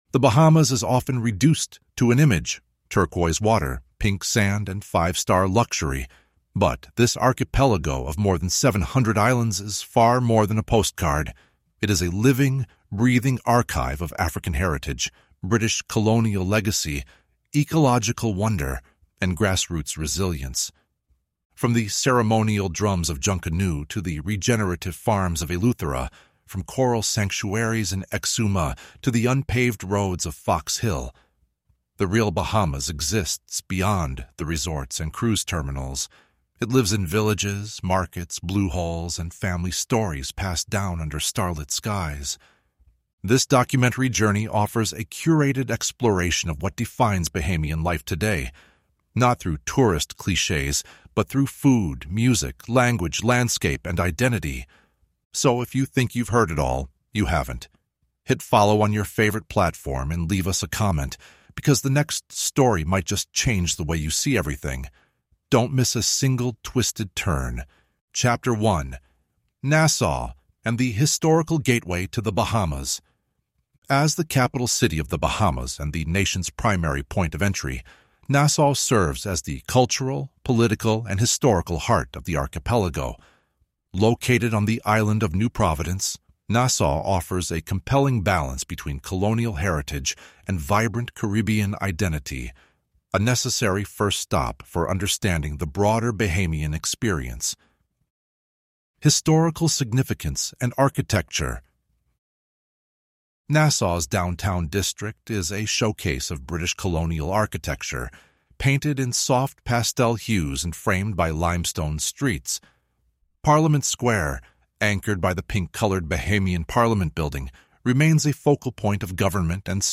Explore The Bahamas like never before with this immersive Caribbean podcast experience that blends Caribbean history, cultural storytelling, and local music with expert travel insights.
Learn how Caribbean women, Maroon communities, and Afro-Caribbean traditions have shaped today’s Bahamian culture. Hear the rhythm of reggae, dancehall, and Jamaican music echo through stories of survival, colonization, and cultural resistance.